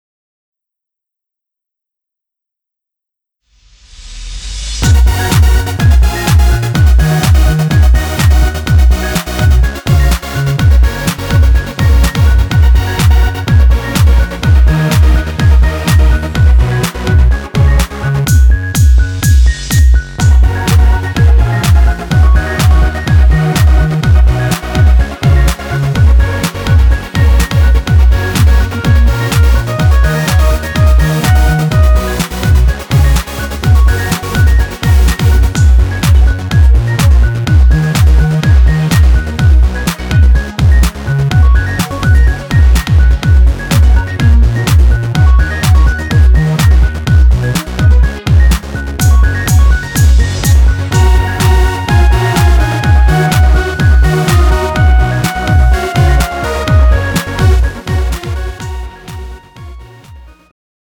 음정 여자-2키
장르 축가 구분 Pro MR